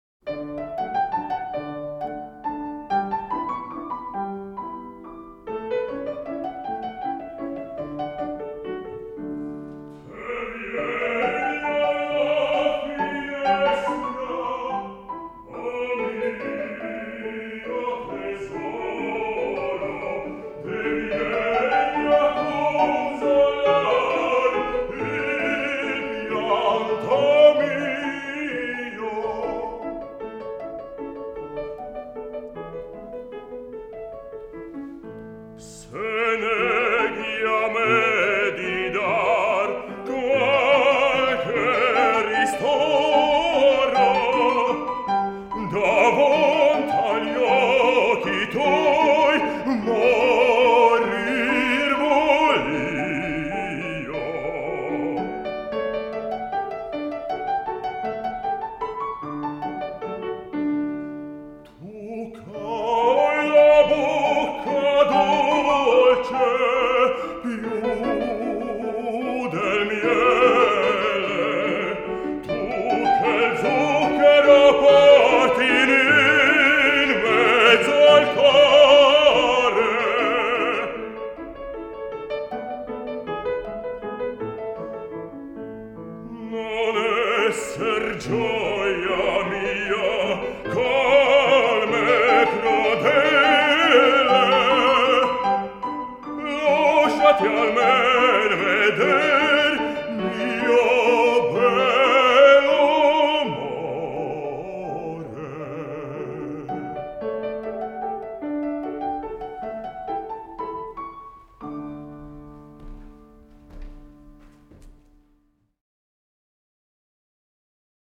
Sākums it kā no tālienes.
Mūzikas ieraksts
Opermūzika
Rīga, Vāgnerzāle